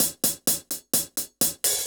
UHH_AcoustiHatA_128-04.wav